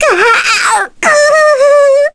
May-Vox_Dead_kr.wav